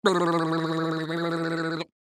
Weird Noise Mouth Warble 1
People Sound Effects
Weird_Noise_Mouth_Warble_1-1-sample.mp3